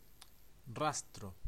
Ääntäminen
US : IPA : [ˈreɪk]